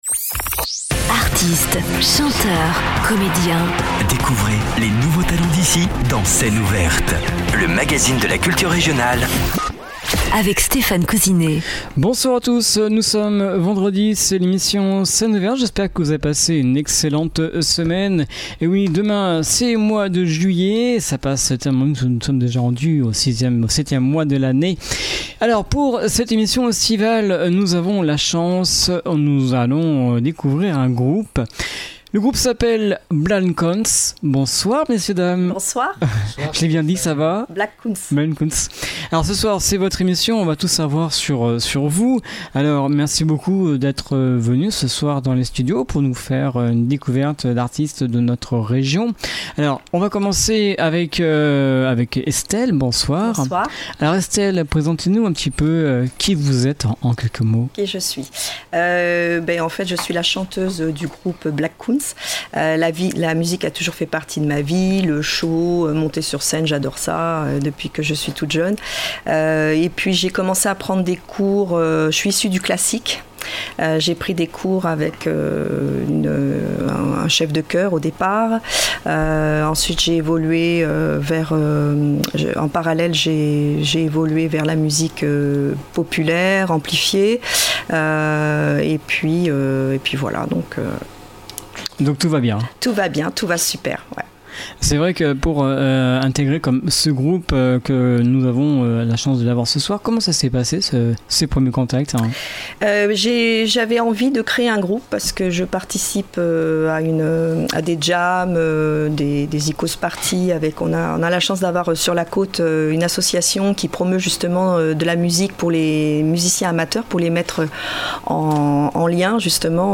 reprises pop-rock